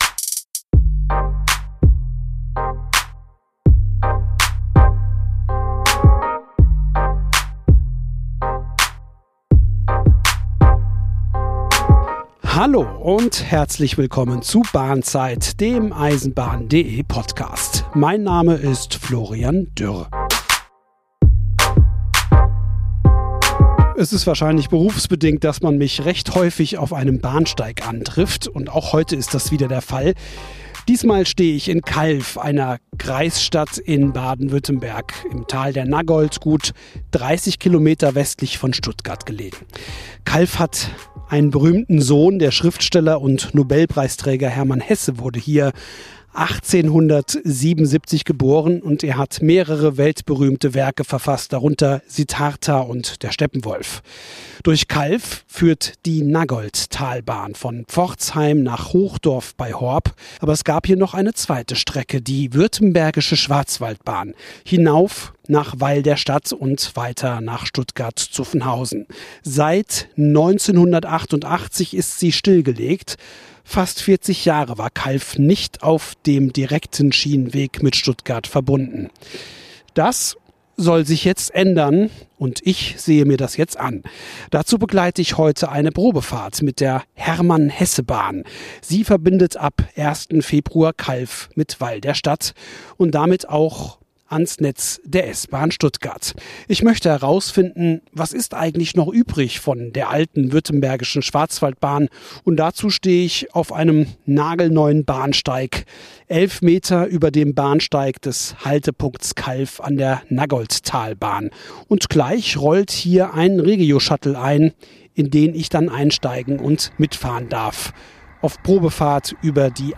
Dazu: Verkehrsminister Winfried Herrmann über Lehren aus dem Mammutprojekt und Landrat Helmut Rieger, der verrät, warum er mindestens zehnmal ans Aufgeben dachte. Eine Geschichte über deutschen Genehmigungswahnsinn, clevere Ingenieurslösungen und die Frage: Wie baut man heute noch Eisenbahn?